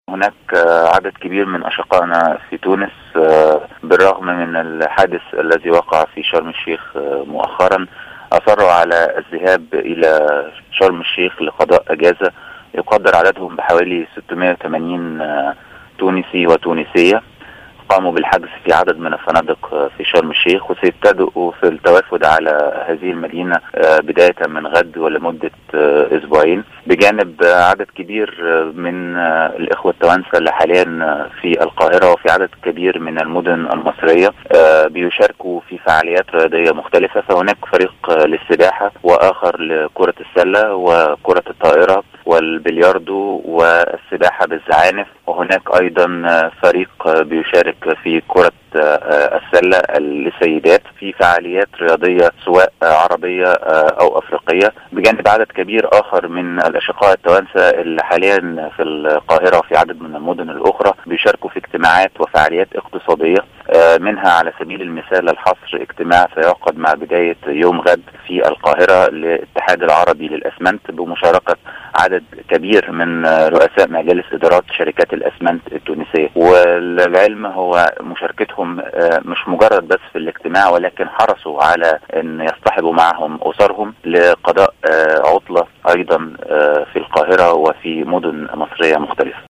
أكد أحمد عزام القنصل المصري في تونس في تصريح للجوهرة "اف ام" عدم إلغاء التونسيين لرحلاتهم إلى شرم الشيخ رغم حادثة سقوط الطائرة الروسية .